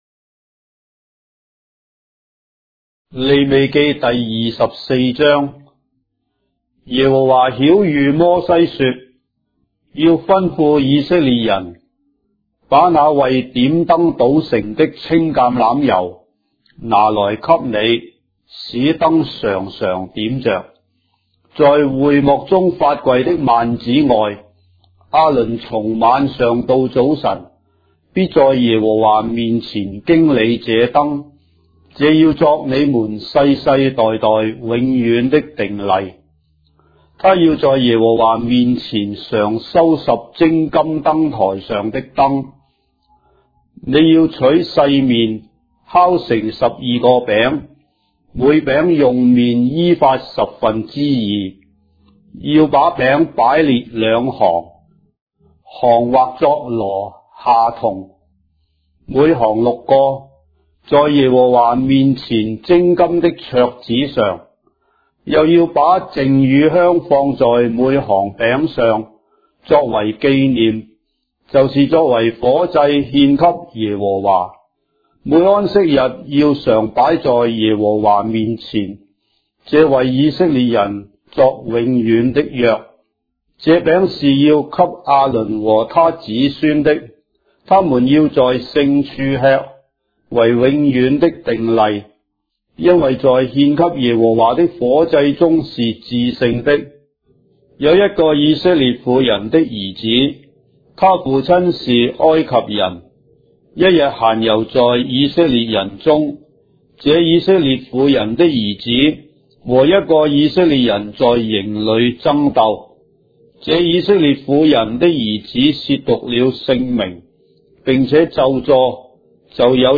章的聖經在中國的語言，音頻旁白- Leviticus, chapter 24 of the Holy Bible in Traditional Chinese